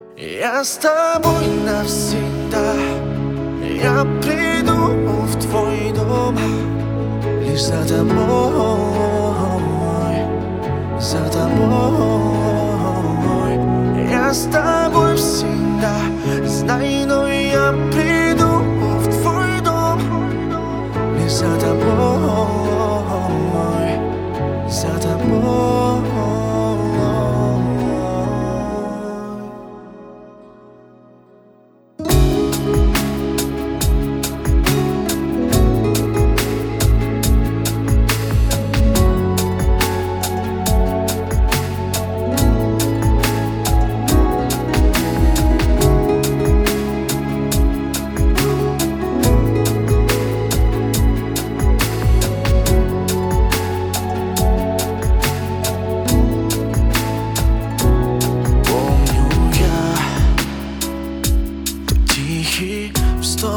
• Качество: 320, Stereo
поп
мужской вокал
спокойные
романтичные
лиричные